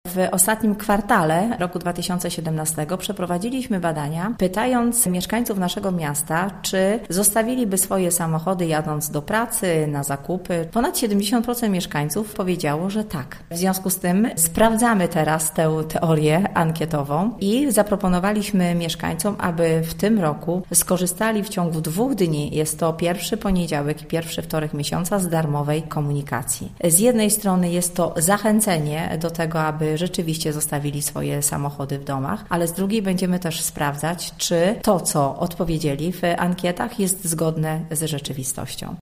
– To jest wynik naszych przemyśleń i badań ankietowych, a darmowa komunikacja pojawia się tytułem próby – mówi Danuta Madej, burmistrz Żar.